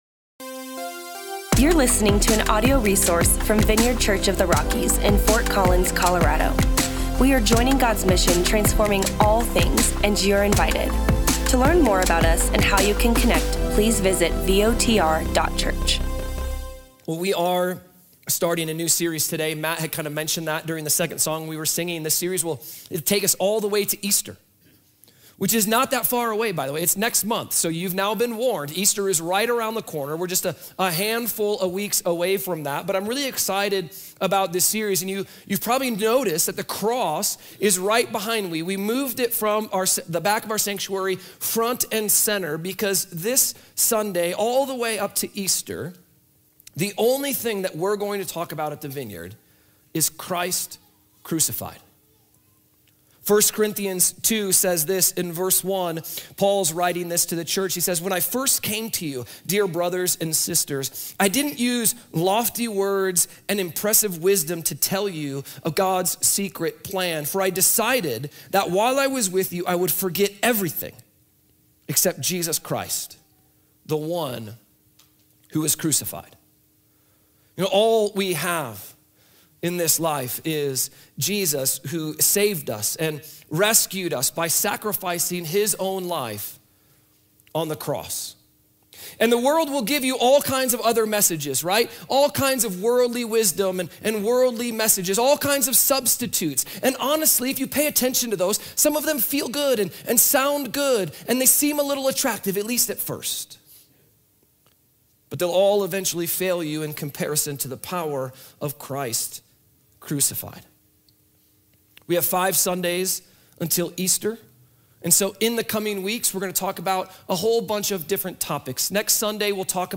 These words from Paul serve as our inspiration for the next five weeks during this sermon series and we invite you to listen to the opening message retelling the story, power, and ‘foolishness’ of Christ Crucified.